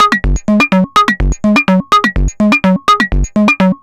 tx_synth_125_runaway.wav